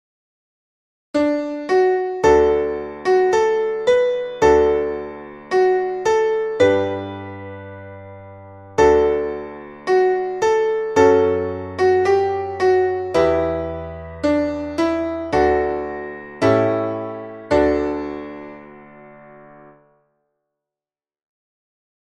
Traditional / Spiritual / Gospel